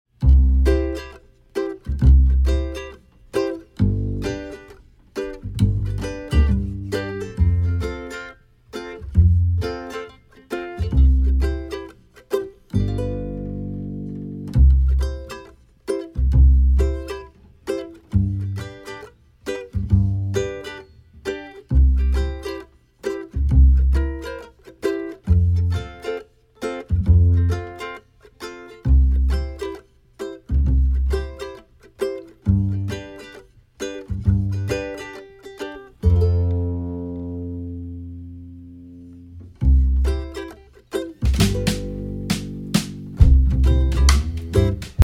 Listen to a sample of the instrumental track.